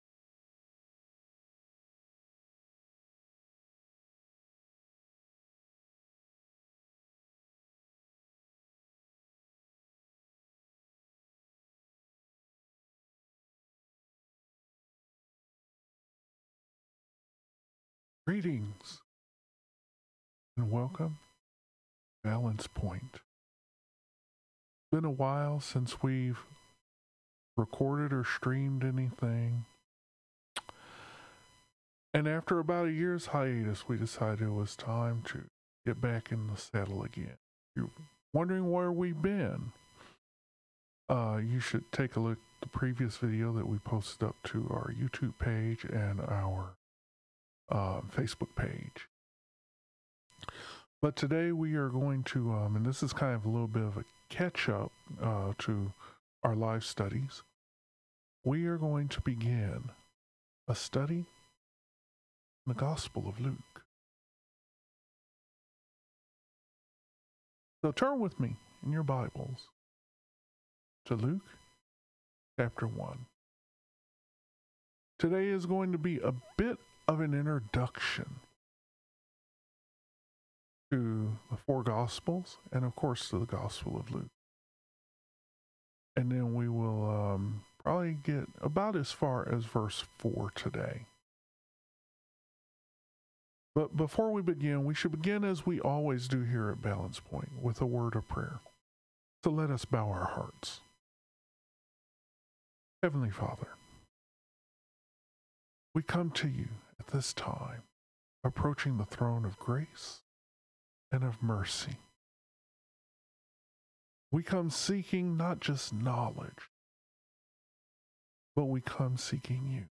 Service Type: Thursday